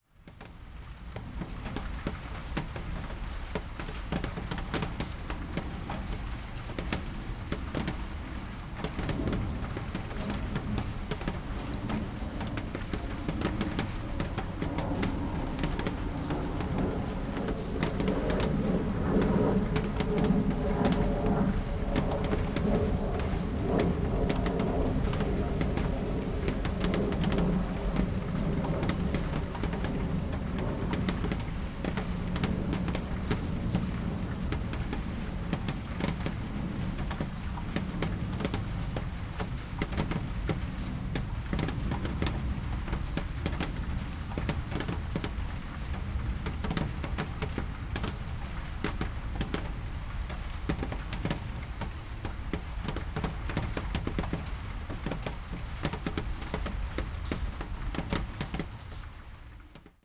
Pluie, fenêtre
Paris 18e, après-midi pour la photo, soir pour le son.
Et puis celle-ci le soir (il n’avait pas arrêté de pleuvoir), avec les meilleurs micros externes, et la transparence sonore de la nuit.